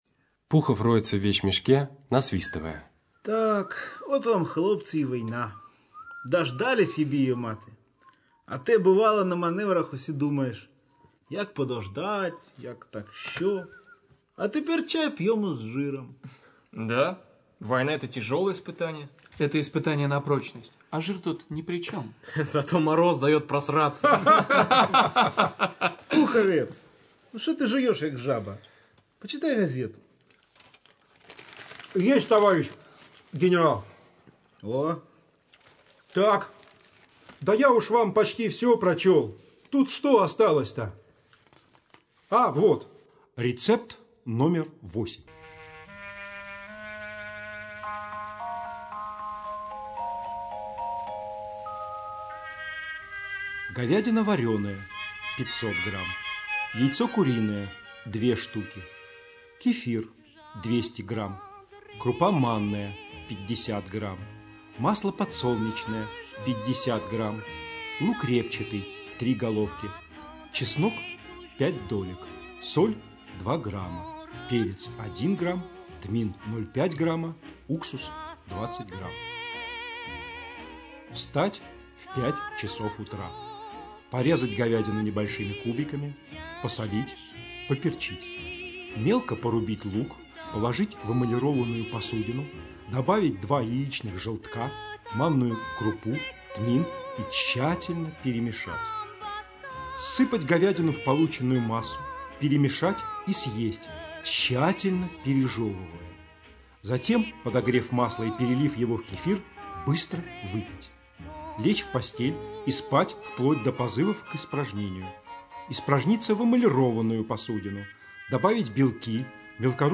Радиопостановка пьесы Владимира Сорокина «Землянка», сделанная во время гастролей любительской театральной труппы журнала Game.EXE в кемеровском ДК «Металлург» в 2003 году.